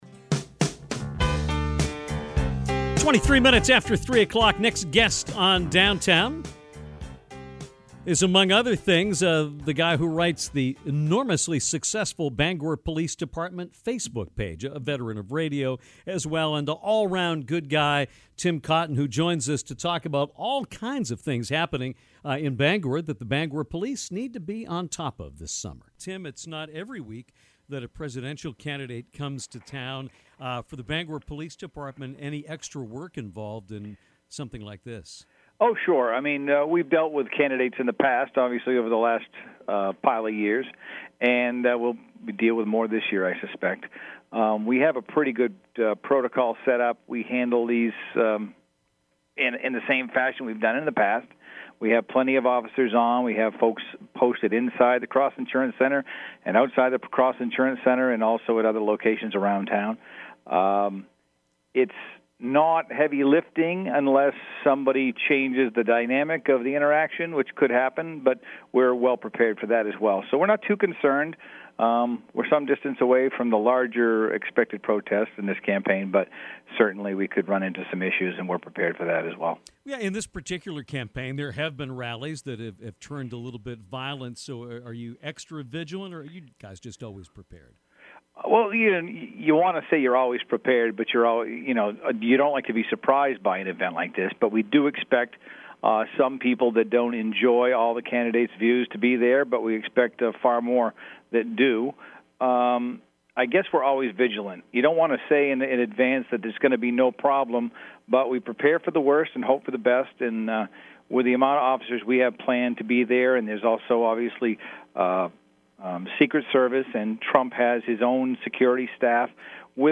Great opening music!